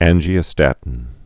an·gi·o·stat·in
(ănjē-ō-stătn)